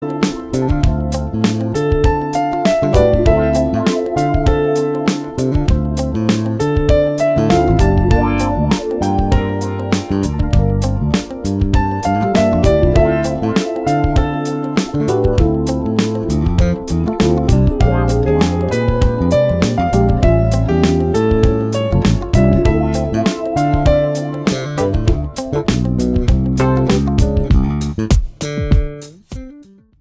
EASY LISTENING  (03.18)